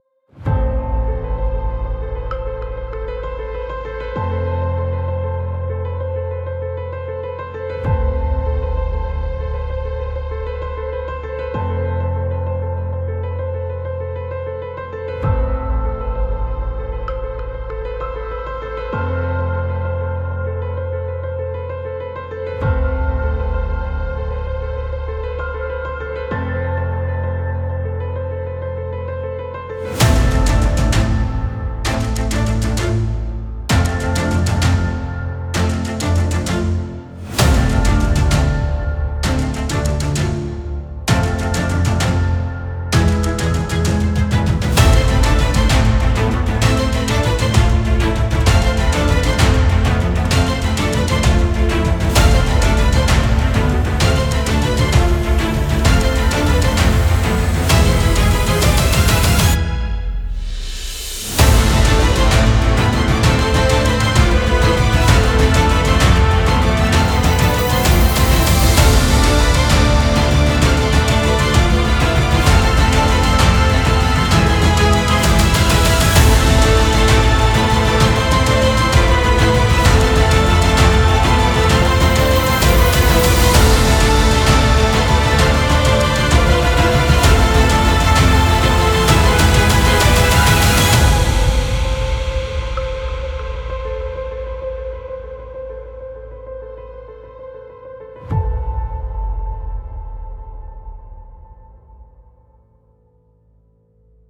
Genre: Film and Media Analysis